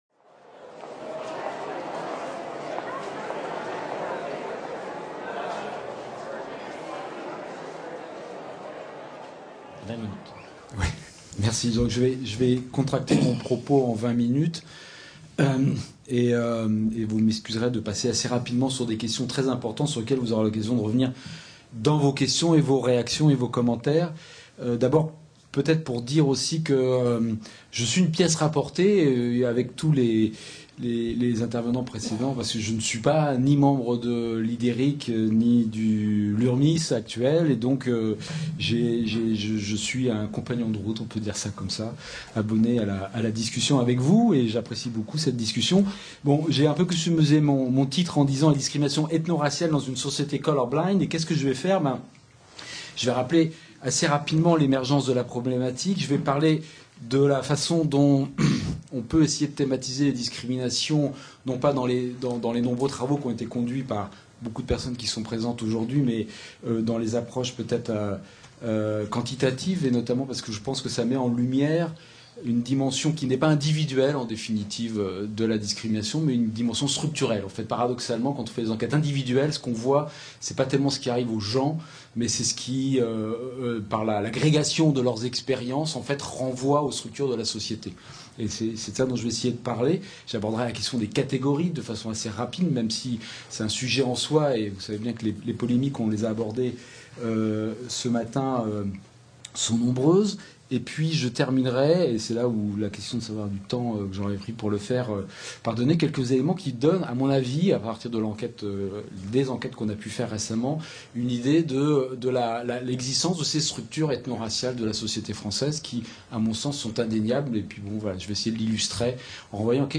Discriminations ethno-raciales dans une société "color blind" Colloque du cinquantenaire de la création du CERIN, Centre d’études des relations interethniques/ Ideric, Institut d’études et de recherches interethniques et interculturelles 3 et 4 mai 2017, MSHS, Université Nice Sophia Antipolis http